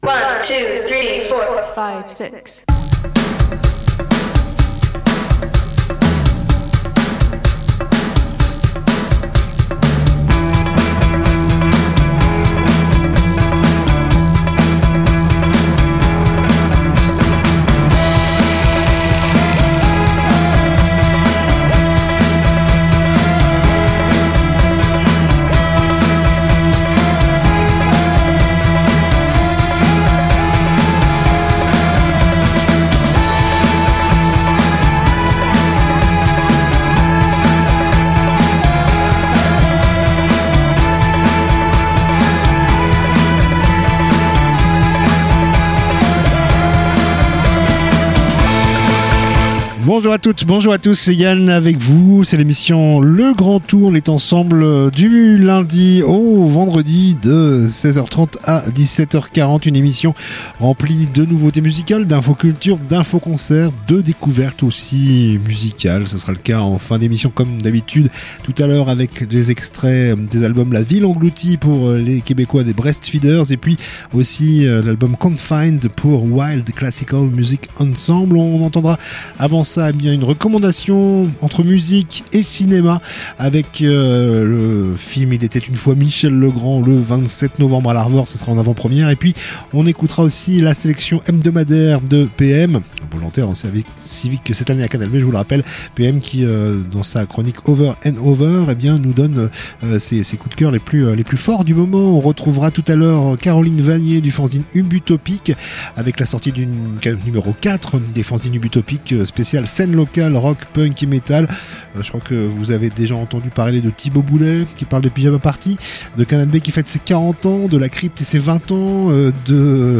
culture Discussion